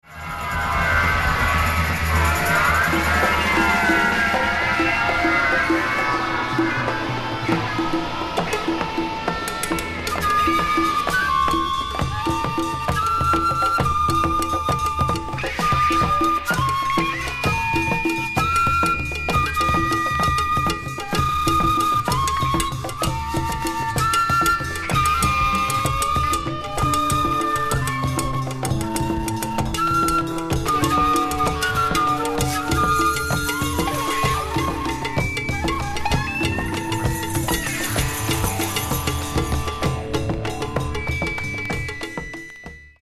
(注) 原盤の状態によりノイズが入っています。